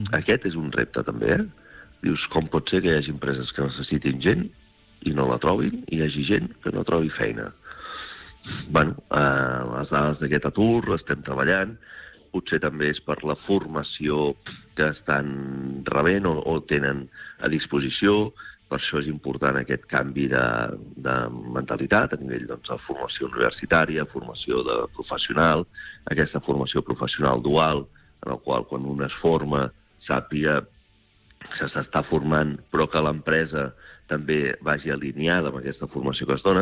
ha fet balanç del 2025 en una entrevista al Supermatí